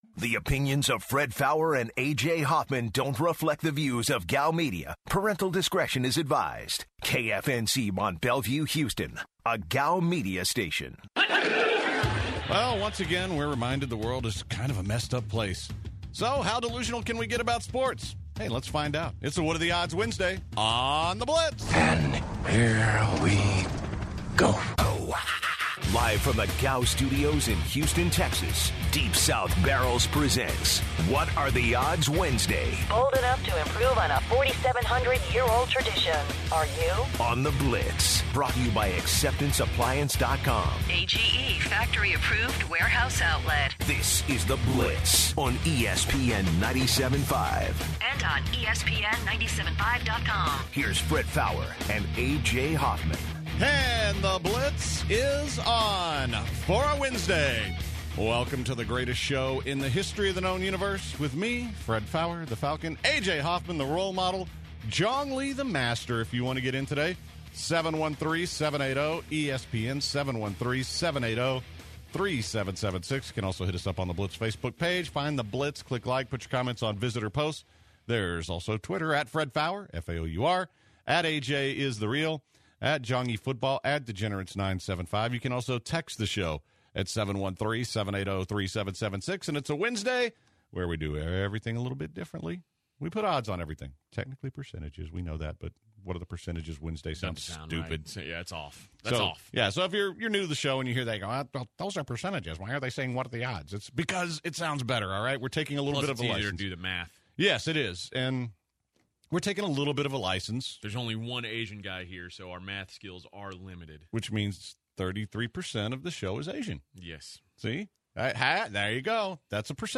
Since it is Wednesday, there will be plenty of What Are The Odds calls from the listeners. The Hour comes to a close with some college football conversations including UH possibly moving conferences and possible changes to the college football playoff structure.